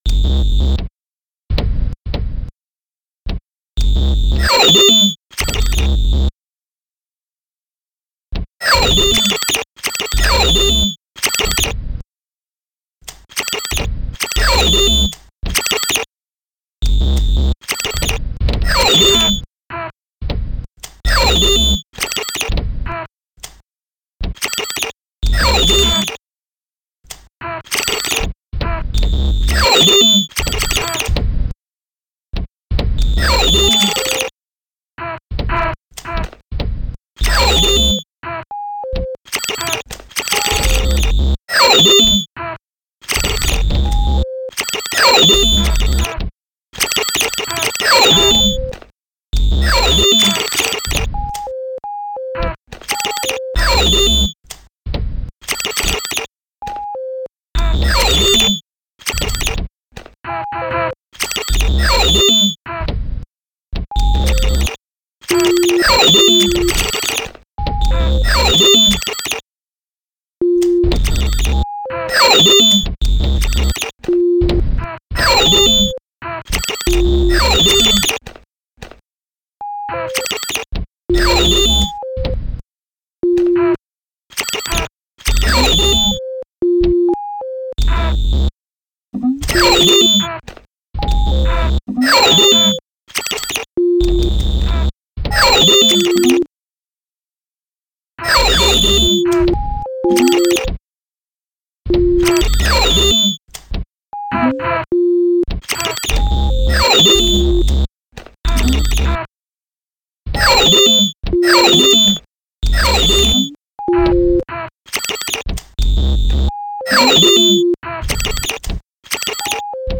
Gitarrensamples
Gitarre & Sequential Circuits Pro One